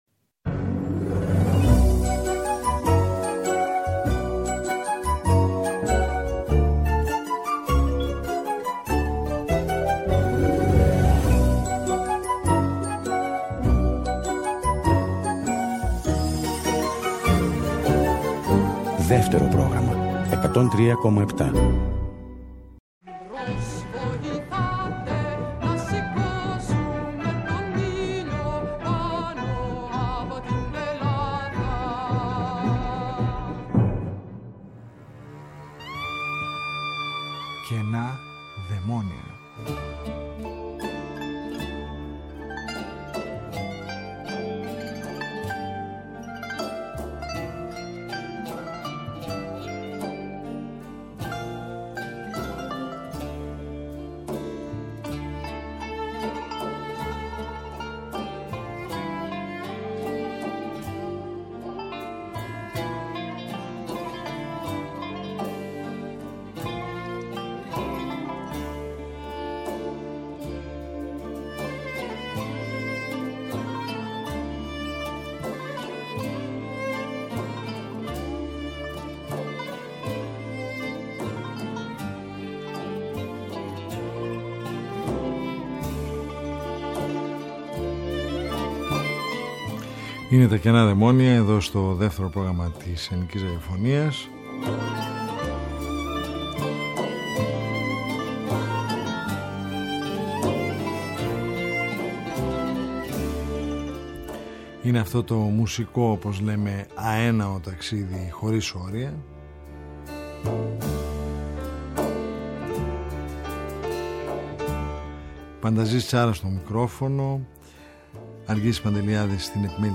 Στην εκπομπή ακούγονται σπάνιες πολυφωνικές στιγμές από την Ελλάδα, την Κορσική και την Γεωργία.